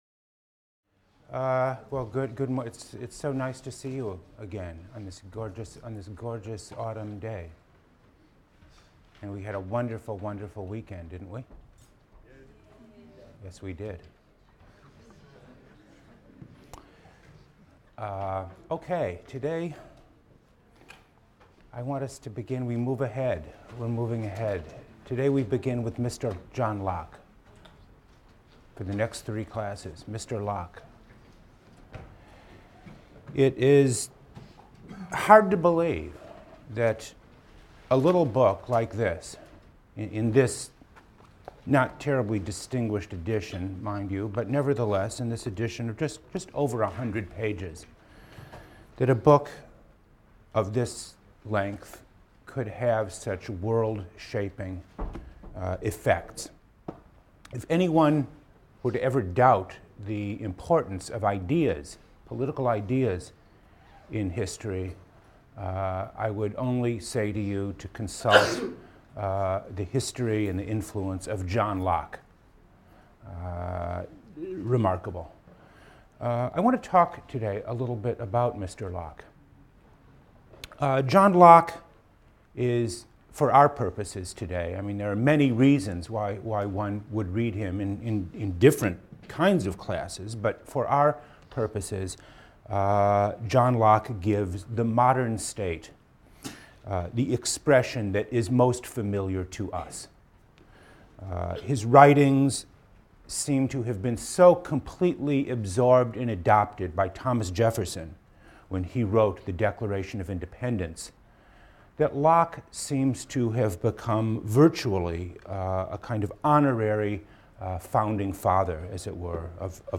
PLSC 114 - Lecture 15 - Constitutional Government: Locke, Second Treatise (1-5) | Open Yale Courses